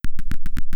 Abstract Rhythm 01.wav